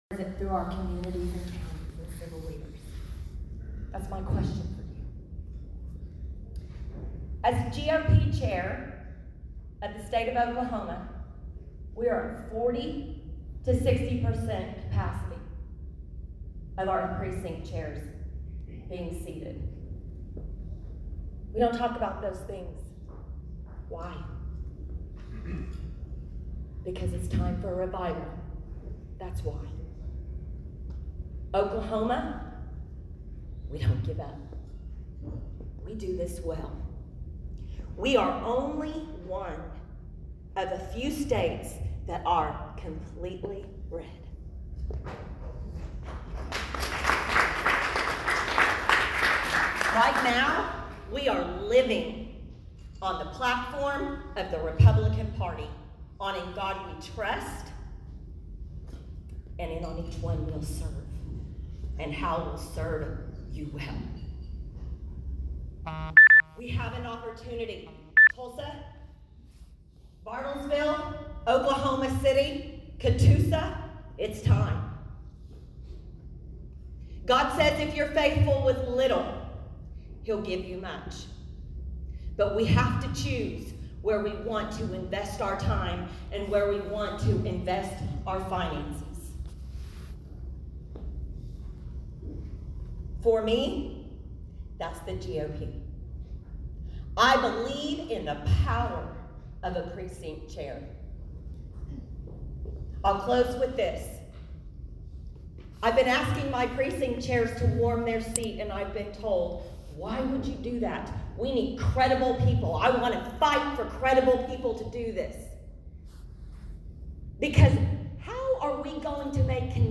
The installation and the presentations took place at the Green Country Republican Women's Club Luncheon on Thursday, March 25, at Hillcrest Country Club in Bartlesville.